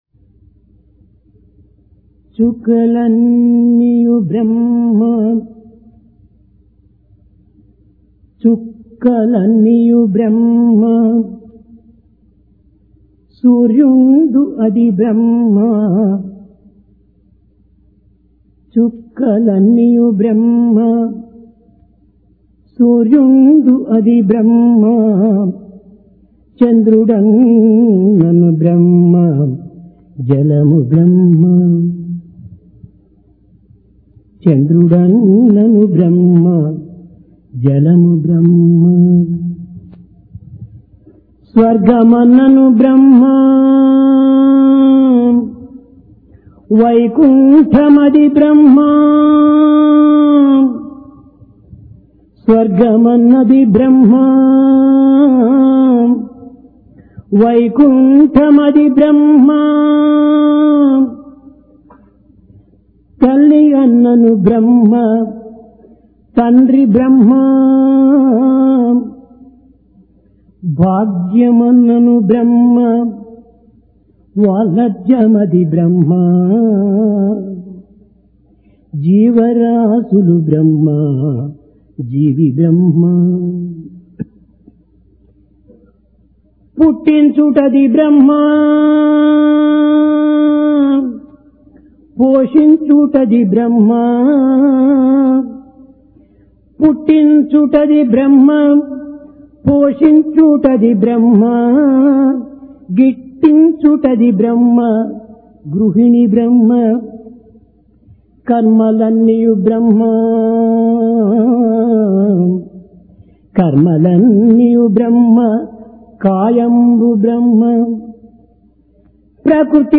Divine discourse on July 30, 1996
Occasion: Guru Poornima Place: Prashanti Nilayam Seek The Guru Within You